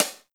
Closed Hats
HIHAT_TIN_CAN.wav